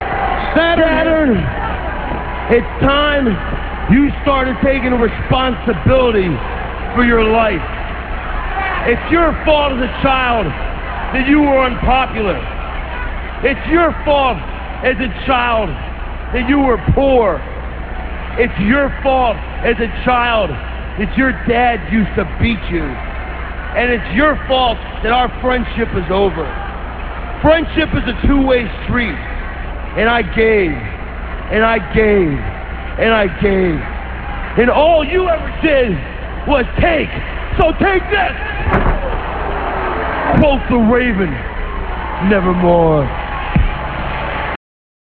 - This speech comes from Nitro - [6.29.98]. Raven comes out and jumps Saturn.